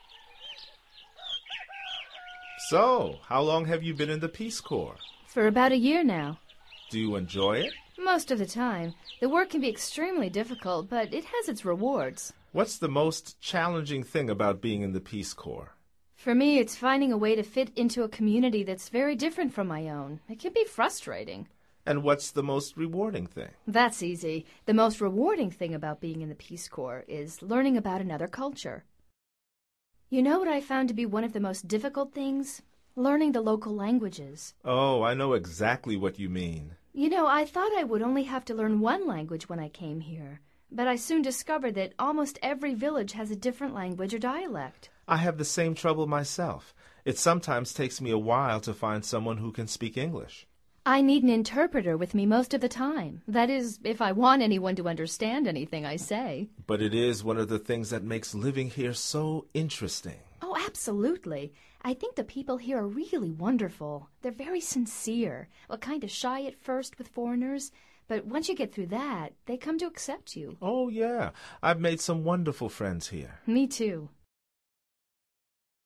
Escucha el audio y concéntrate en la entonación y ritmo de las frases.